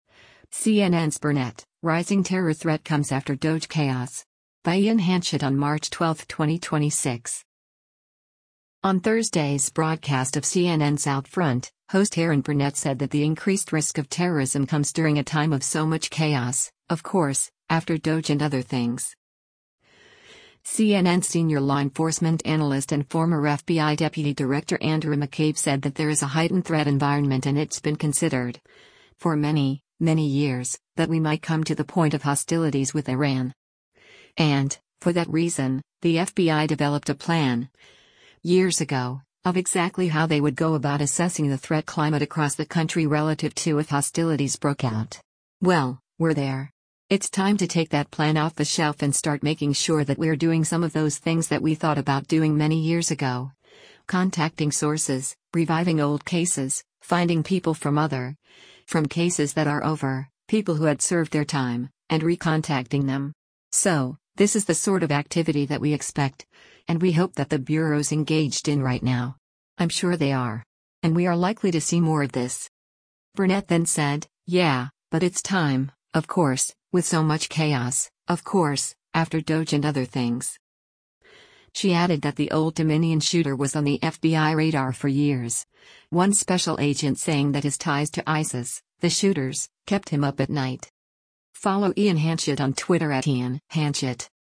On Thursday’s broadcast of CNN’s “OutFront,” host Erin Burnett said that the increased risk of terrorism comes during a time of “so much chaos, of course, after DOGE and other things.”